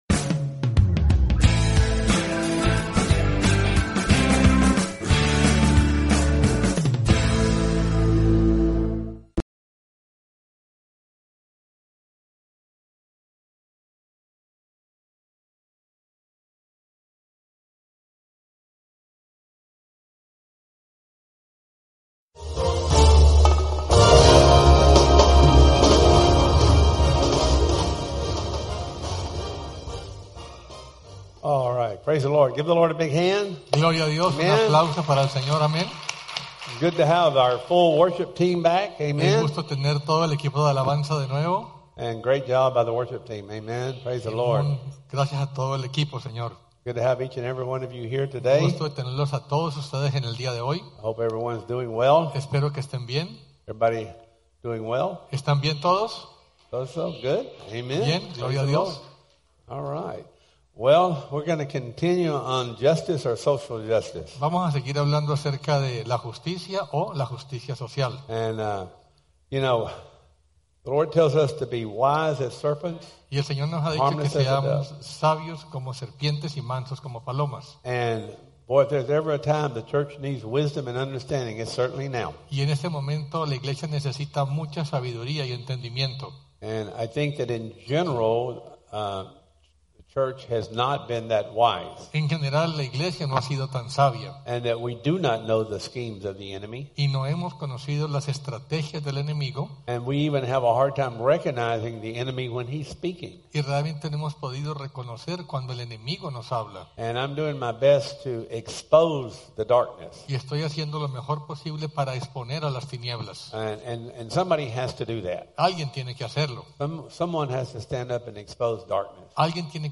Wisdom for Vision Service Type: Sunday Service « Justice or Social Justice?